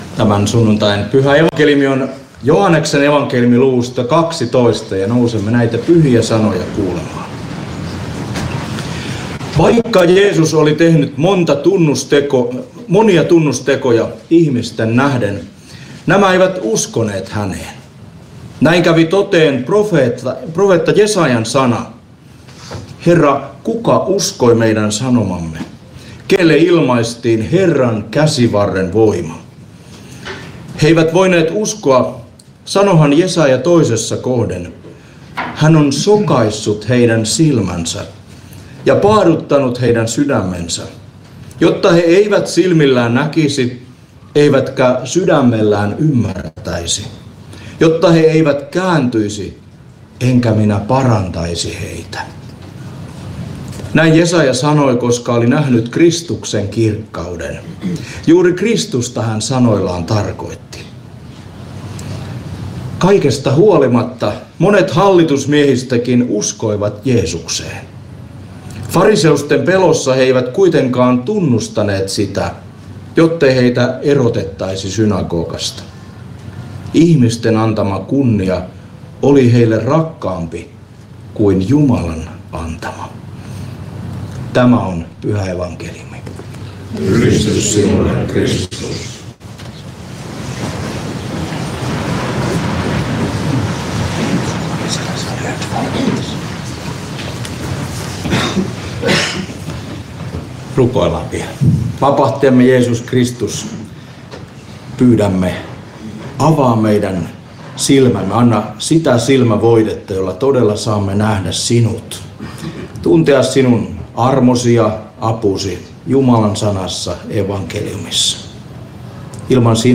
Porissa 3. paastonajan sunnuntaina Tekstinä Joh. 12:37–43